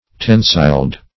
tensiled - definition of tensiled - synonyms, pronunciation, spelling from Free Dictionary
Tensiled \Ten"siled\